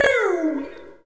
Track 14 - Vocal Bing OS.wav